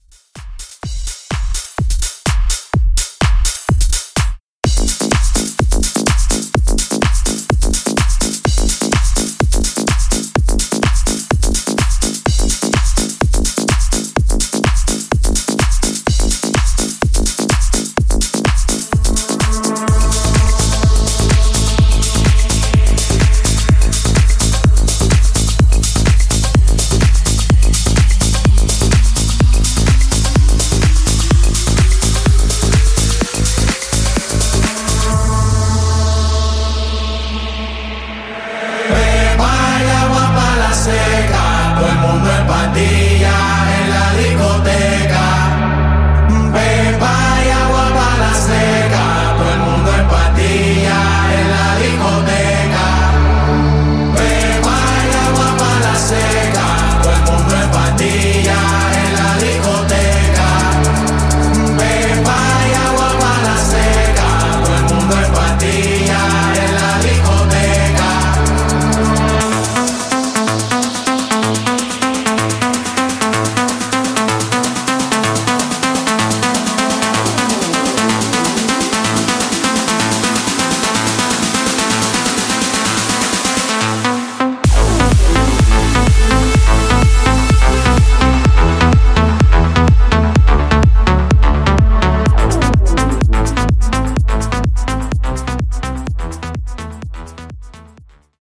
In-Outro House Edit)Date Added